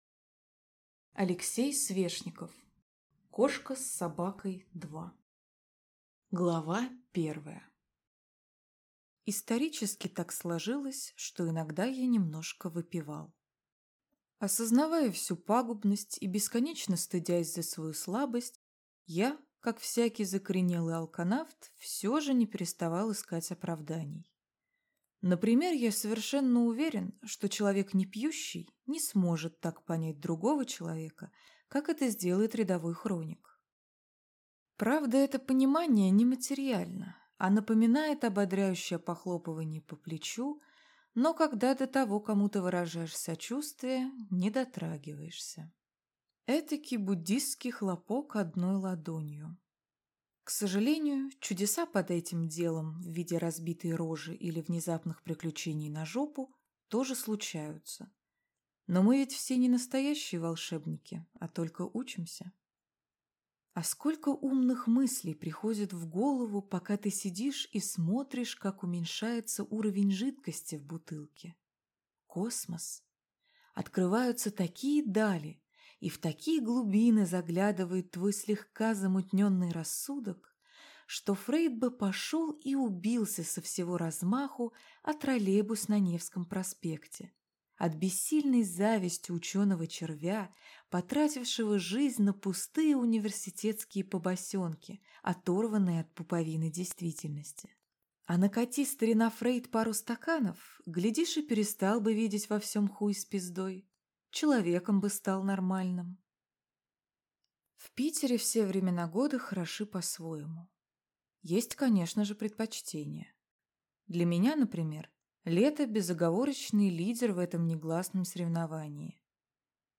Аудиокнига Кошка с Собакой 2 | Библиотека аудиокниг